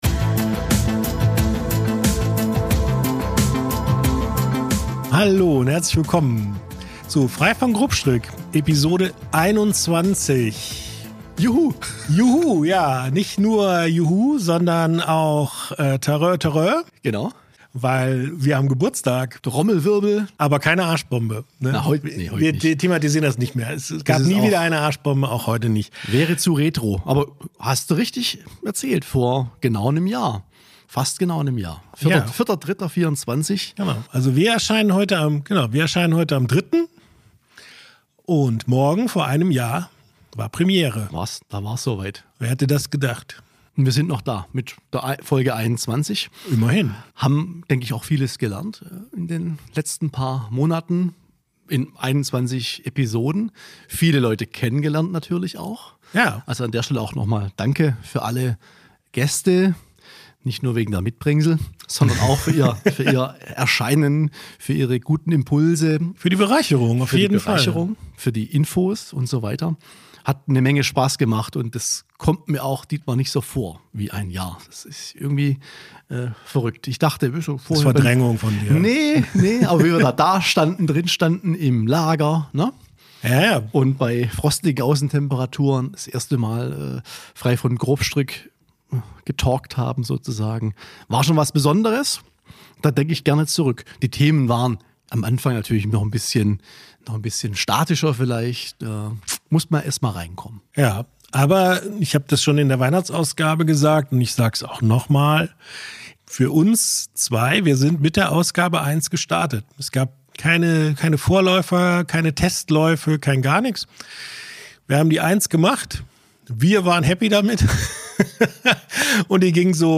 Heute treffen sich die beiden in der Vinothek Weinkorb, um Episode 21 aufzunehmen und blicken dabei auf ein ereignisreiches Jahr zurück.
Locker bis launig, meinungsstark und informativ, das ist Frei von Grobstrick, der HeizungsJournal-Podcast.